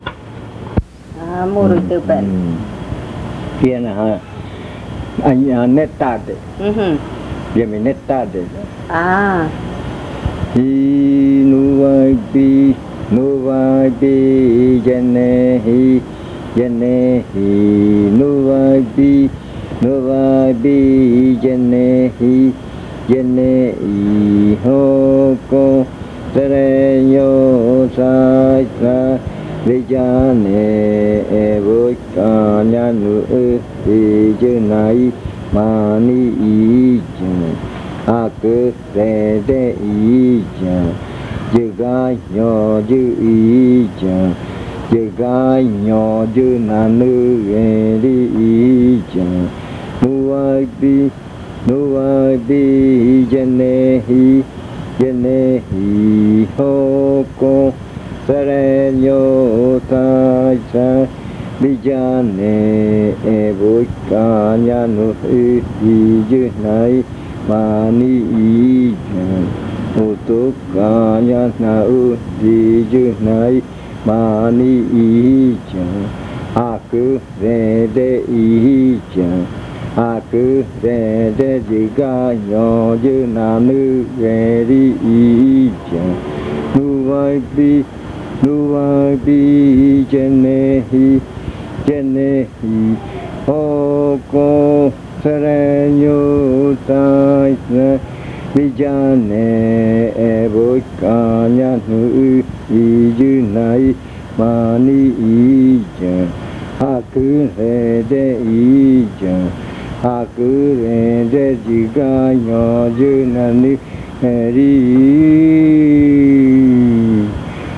Leticia, Amazonas
Canción de arrimada (zɨjɨa rua), la que abre la fiesta.
Entry chant (zɨjɨa rua), the one that opens the ritual.